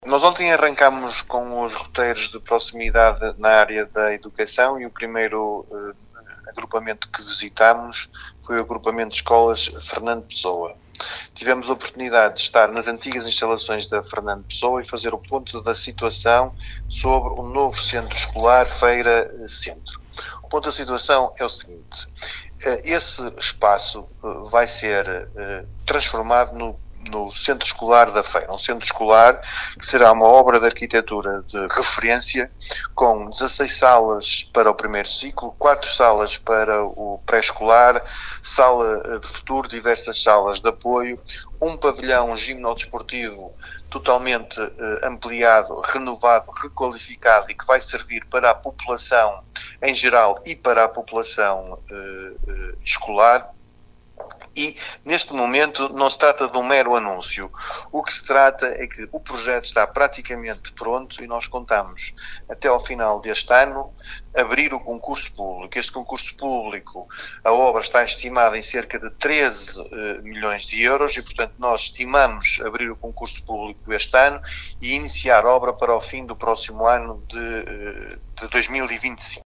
Aos microfones da Sintonia, o autarca realçou algumas das intervenções previstas na escola, num investimento que ronda os 13 milhões de euros.